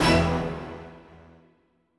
SouthSide Stab.wav